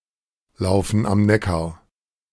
Lauffen am Neckar (German pronunciation: [ˌlaʊfn̩ ʔam ˈnɛkaʁ]